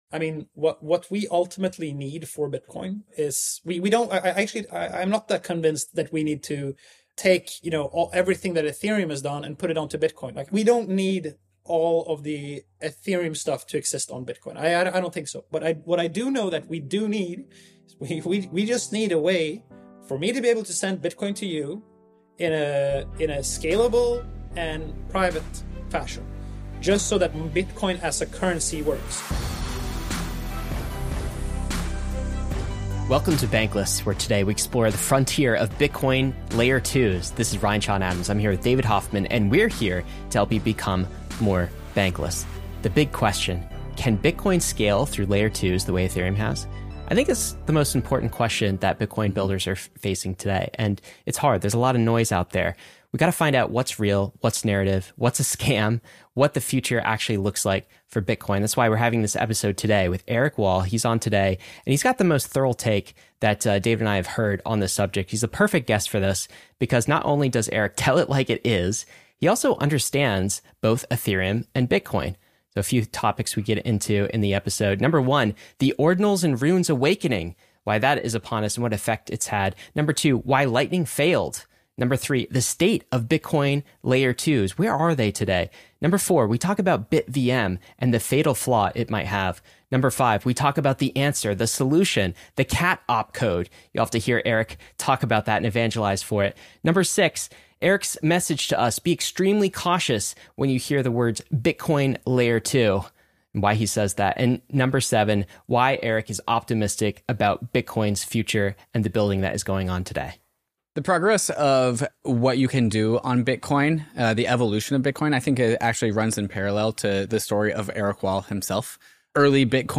In this discussion